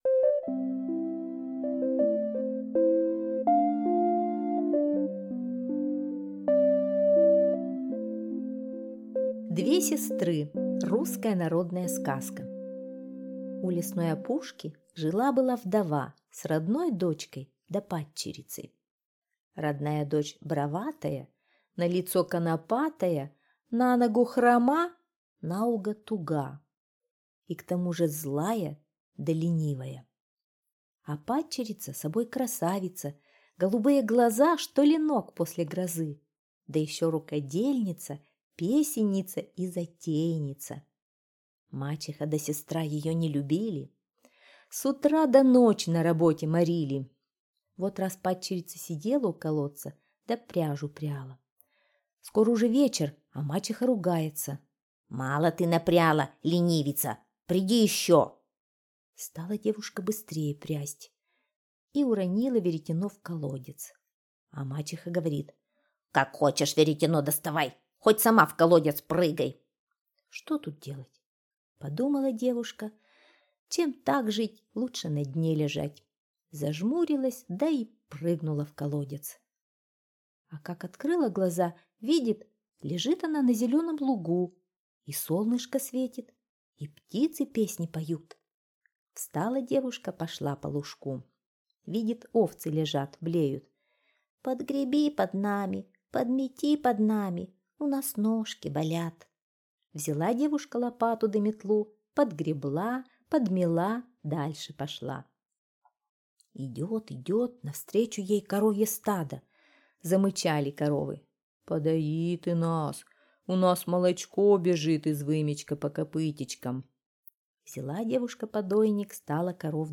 Две сестры — русская народная аудиосказка.